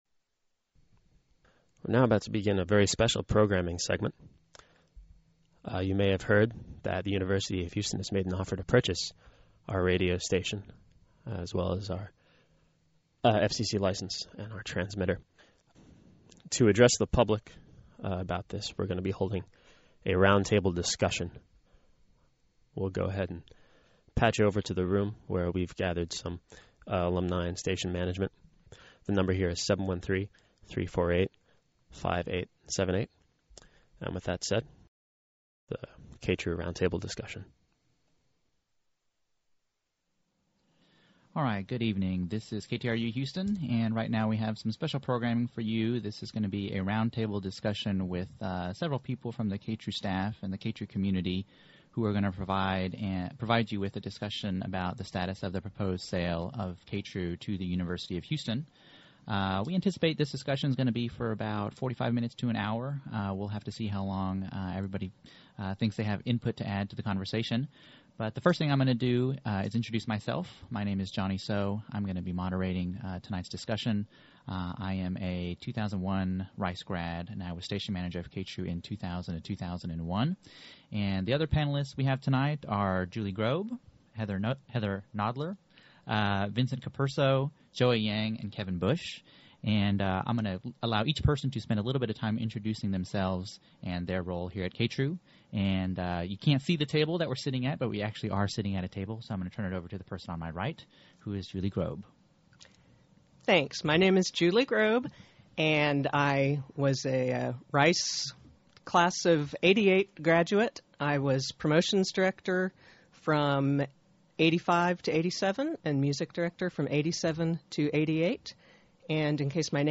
On Saturday 9/4 at 8PM, a group of KTRU DJs, alumni, and student leaders discussed KTRU’s mission and history vis-a-vis the recent sale controversy.
roundtable2.mp3